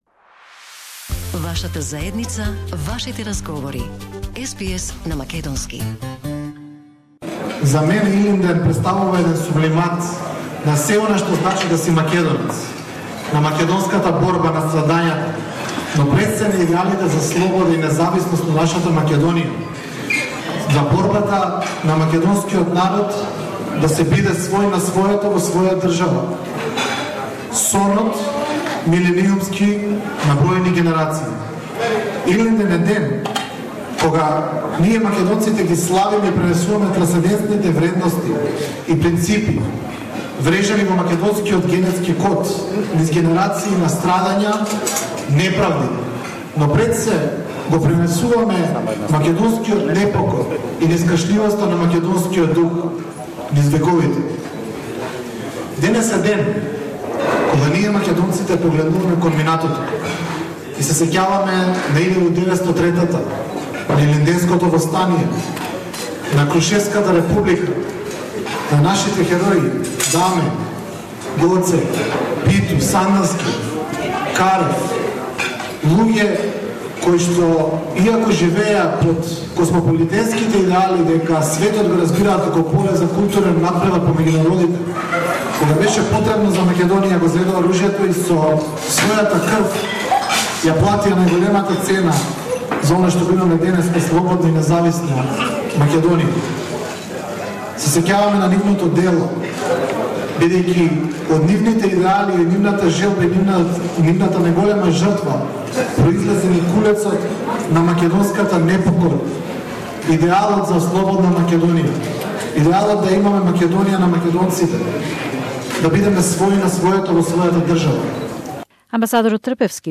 We, like the Ilinden heroes, understand the world as a field for cultural competition between people ", said the Ambassador of the Republic of Macedonia to Australia, H.E Vele Trpevski at the Ilinden celebration at the Rockdale Ilinden sports club in Sydney